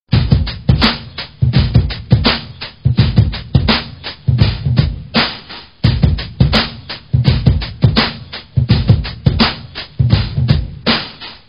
But in our examples we use samples with low quality because they are more fast in downloading.
You will not have to do anything with this file - just memorize some numbers: the file is of 11,45 seconds length and there are 8 beats in the file.
drum.wav